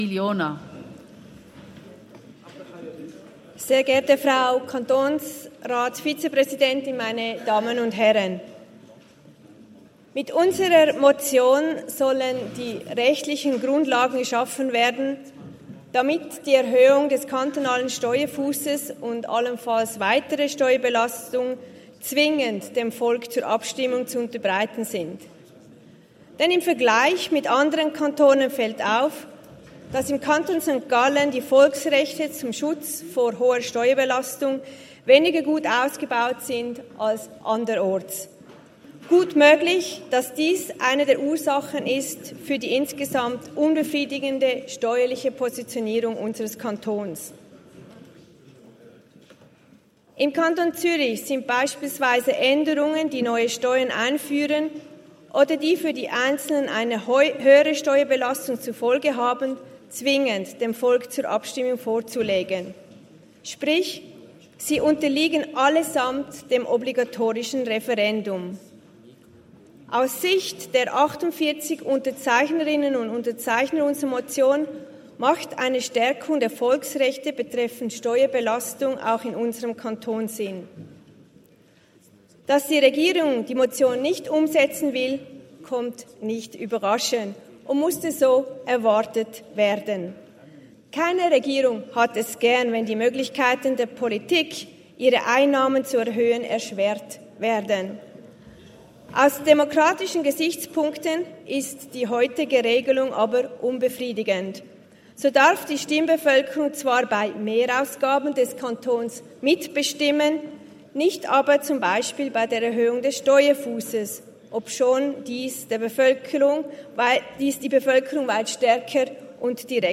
28.11.2023Wortmeldung
Session des Kantonsrates vom 27. bis 29. November 2023, Wintersession